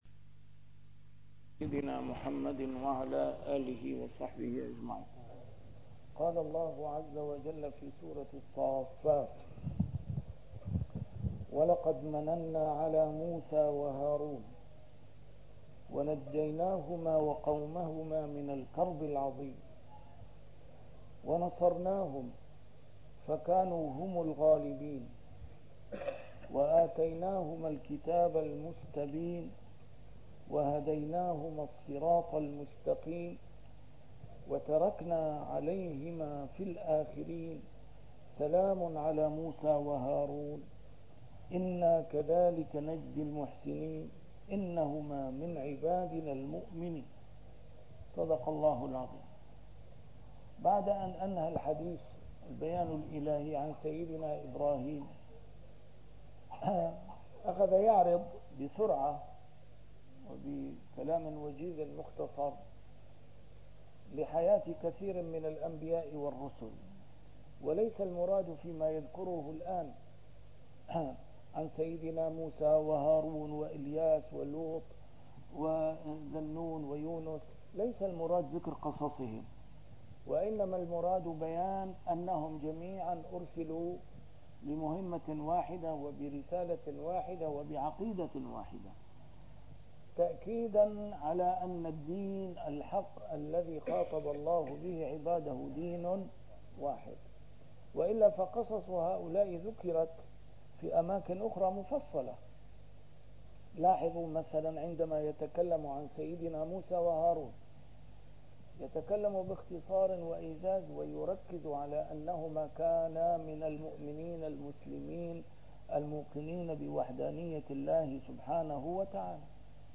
A MARTYR SCHOLAR: IMAM MUHAMMAD SAEED RAMADAN AL-BOUTI - الدروس العلمية - تفسير القرآن الكريم - تسجيل قديم - الدرس 456: الصافات 114-126